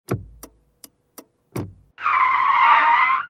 switch.ogg